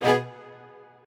admin-leaf-alice-in-misanthrope/strings34_3_005.ogg at a8990f1ad740036f9d250f3aceaad8c816b20b54